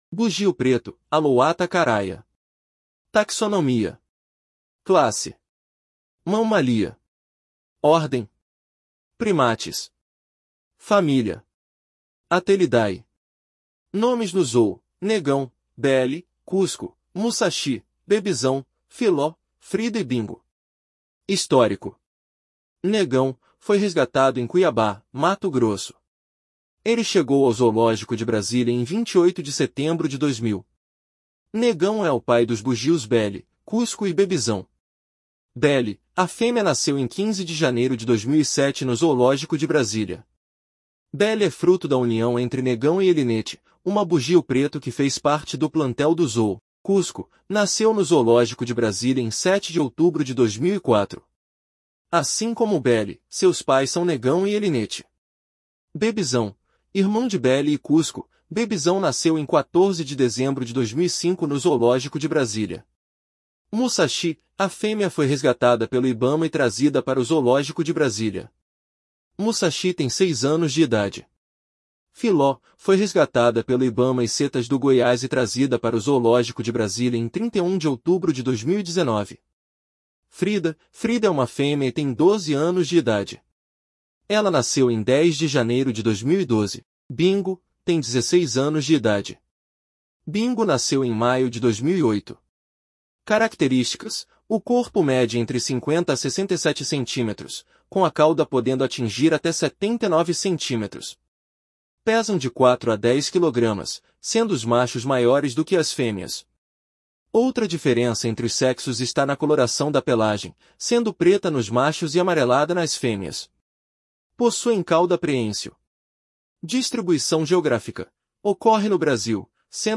Bugio-preto (Alouatta caraya)
Curiosidades: Estes primatas possuem um osso na garganta modificado que os possibilita fazer vocalizações muito altas. Estes “gritos” podem ser ouvidos a grandes distâncias, sendo usados para demarcação territorial e união do grupo. Esse comportamento acontece principalmente ao amanhecer e entardecer, sendo o coro iniciado pelo macho alfa.